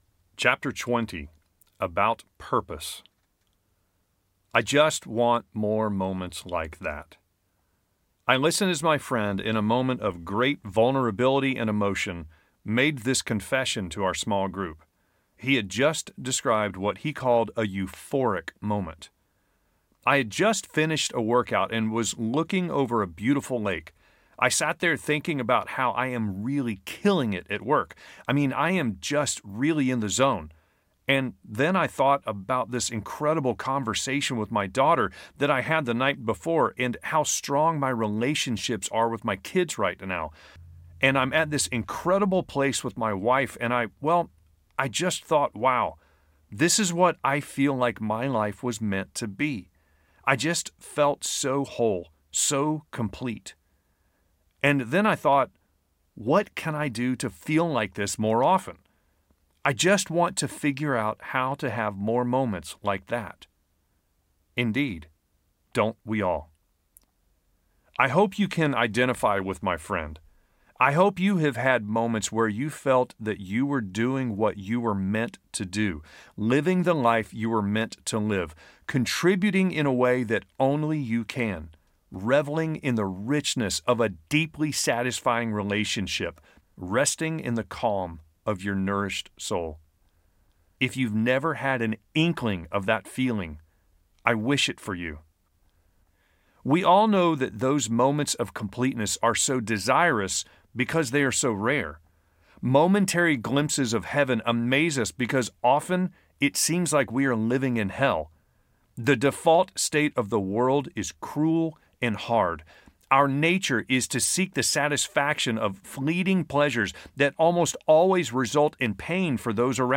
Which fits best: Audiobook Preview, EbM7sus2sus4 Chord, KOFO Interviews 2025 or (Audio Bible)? Audiobook Preview